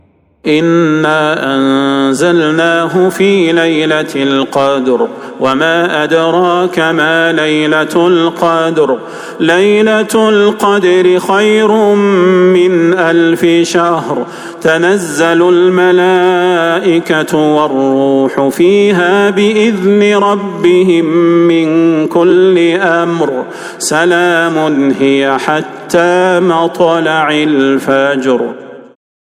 سورة القدر | رمضان 1445هـ > السور المكتملة للشيخ صلاح البدير من الحرم النبوي 🕌 > السور المكتملة 🕌 > المزيد - تلاوات الحرمين